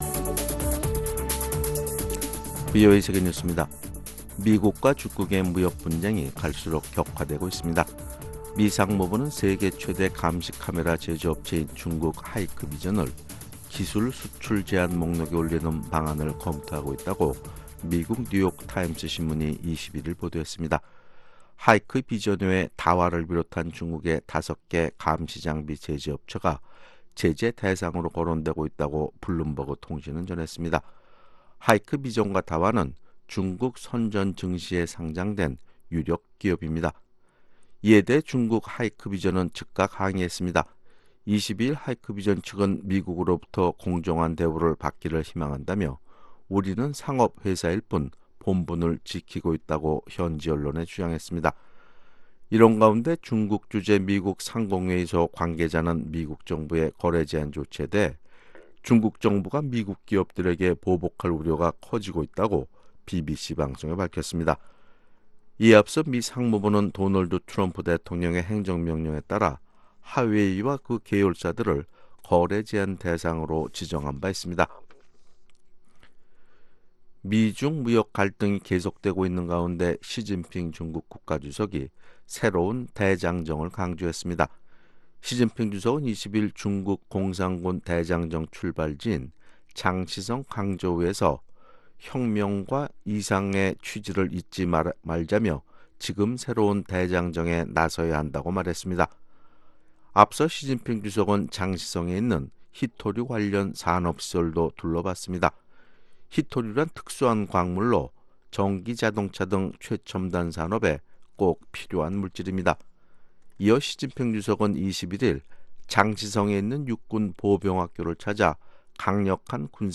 VOA 한국어 아침 뉴스 프로그램 '워싱턴 뉴스 광장' 2019년 5월 23일 방송입니다. 올해 상반기 미국 의회에서 발의된 한반도 외교 안보 관련 법안과 결의안이 총 10건으로 북한 문제에 대한 미 의회의 지속적인 관심이 이어지고 있습니다. 미국 국무부는 압류 화물선에 대한 즉각 반환을 주장하는 김성 유엔주재 북한 대사의 요구에 대북 제재는 그대로 유지되고 모든 유엔 회원국들에 의해 이행될 것이라고 밝혔습니다.